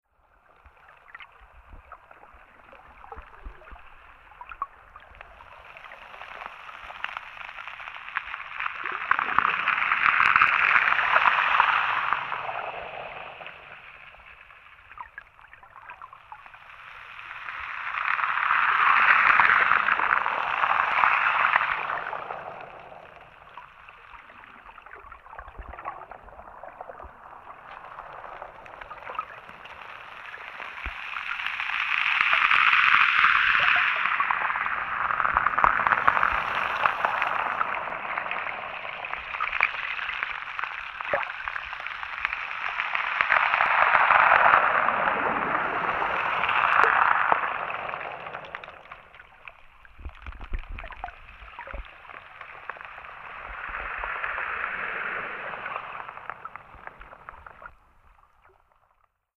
underwater near the pier in Brighton
brighton_underwater.mp3